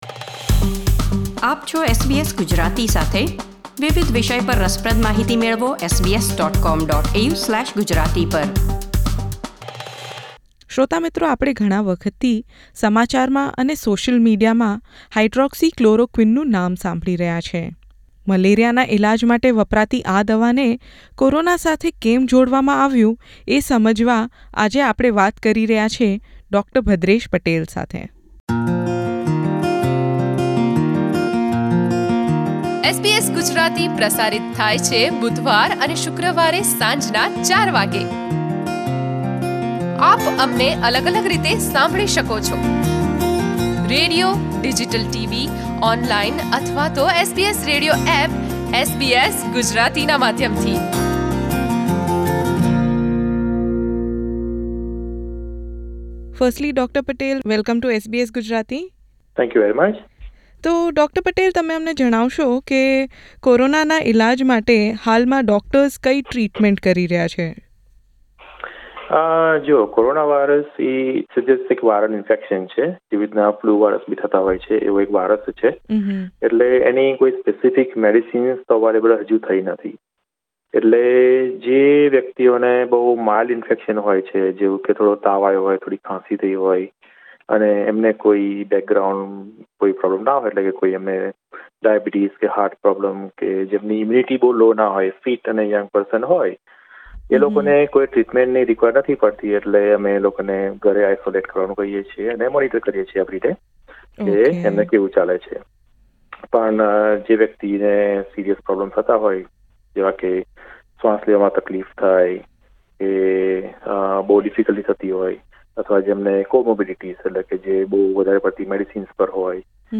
In a conversation with SBS Gujarati